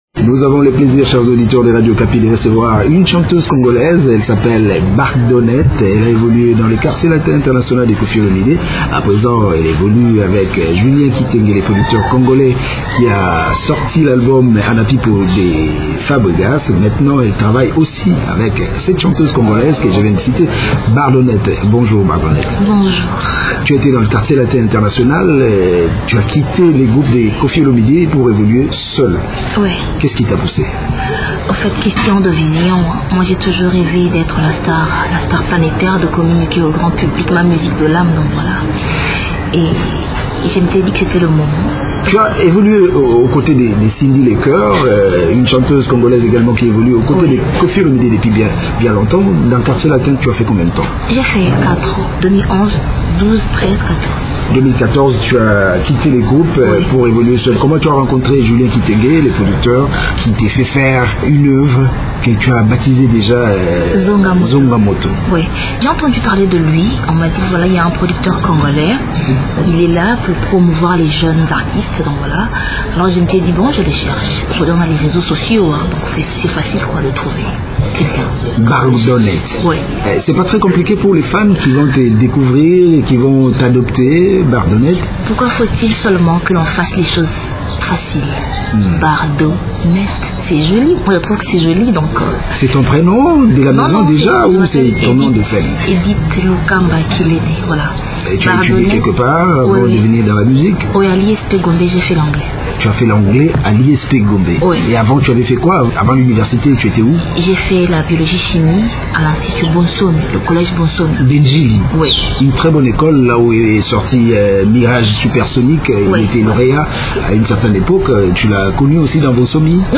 Elle parle de son single au micro